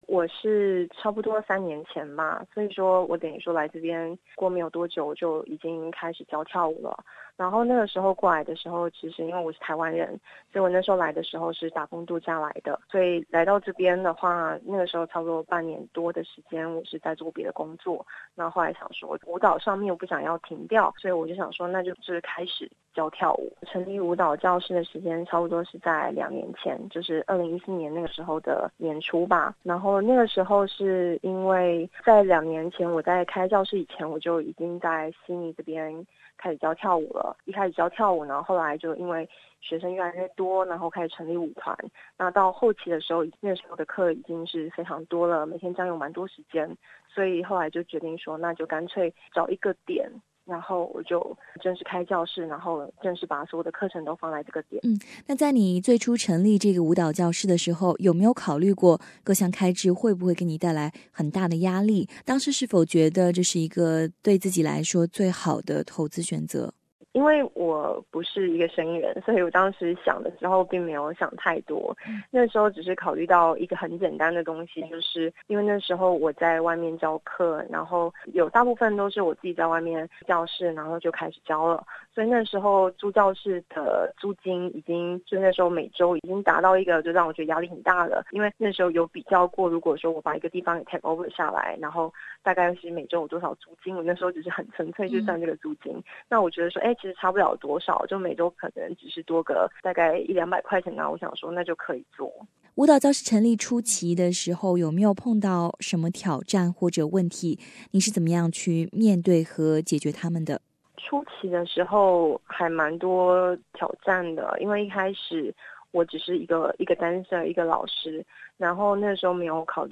澳洲华商访谈系列之